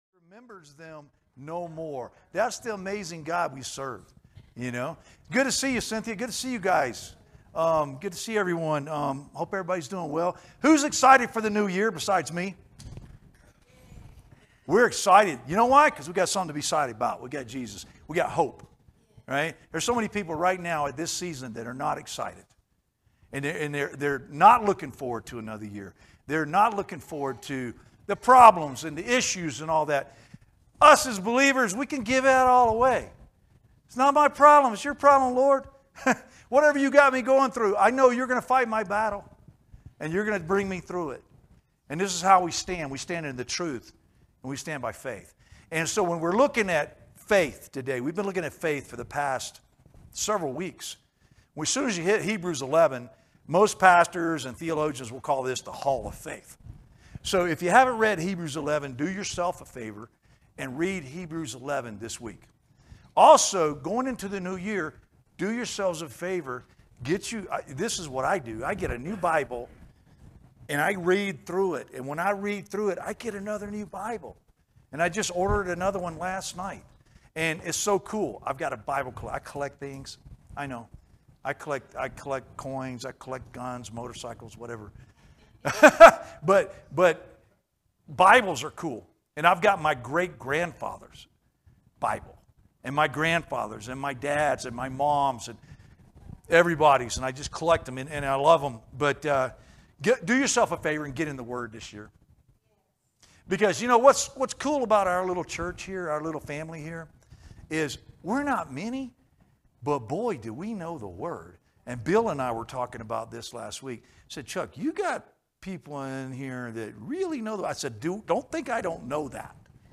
teaches a lesson from the book of Hebrews, Chapter 11